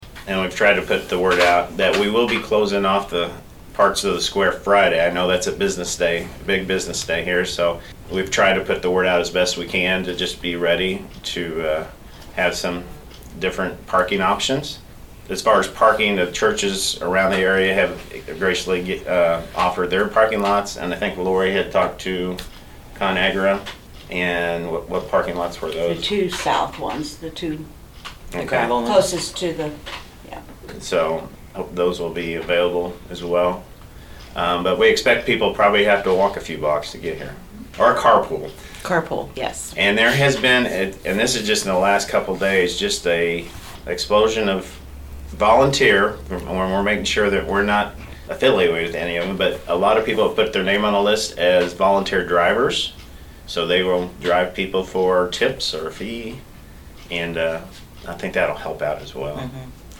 was at the meeting of the Saline County Commission on Thursday, June 9, and talked about some of the logistics involving street closures and parking.